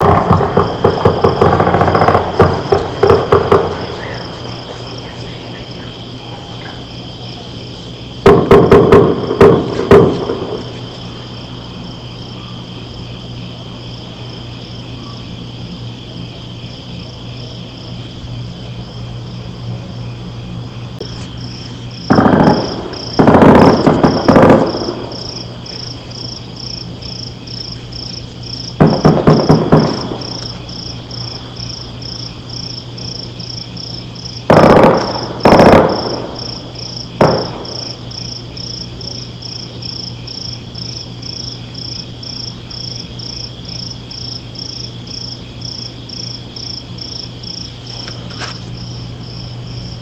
audio-balas-1.mp3